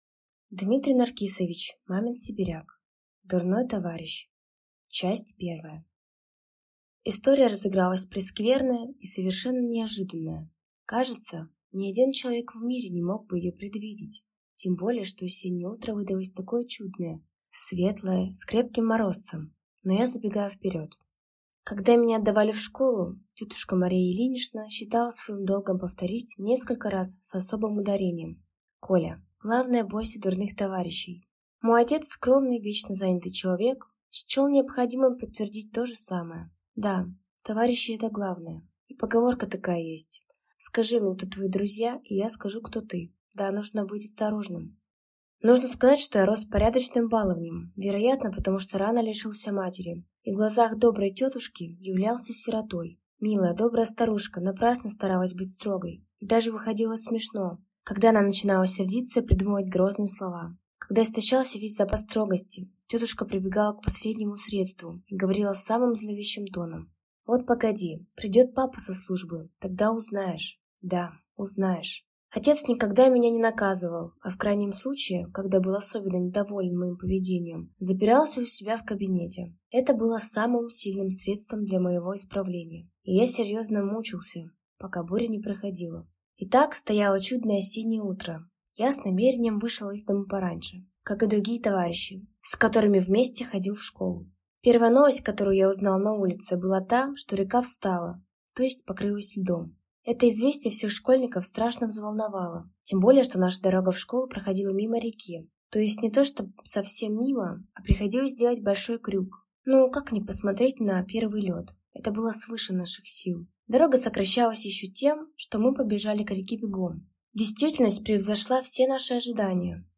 Аудиокнига Дурной товарищ | Библиотека аудиокниг
Прослушать и бесплатно скачать фрагмент аудиокниги